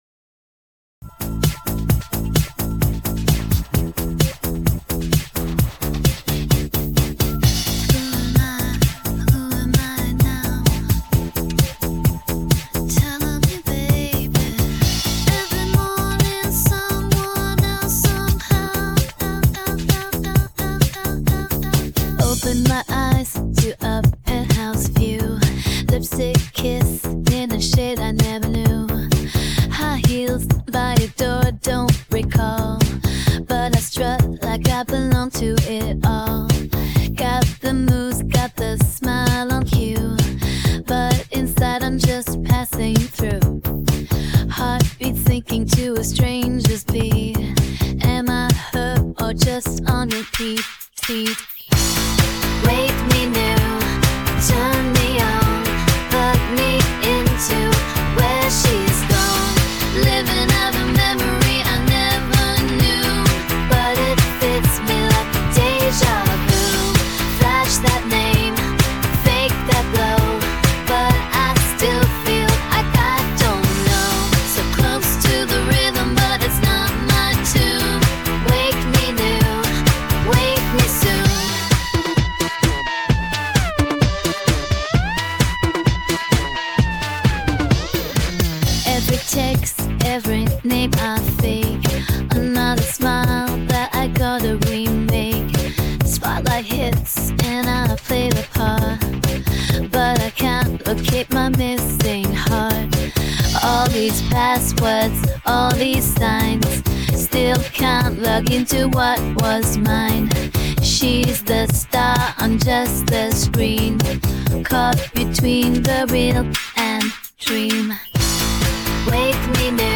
• Genre: Indie Pop/Electro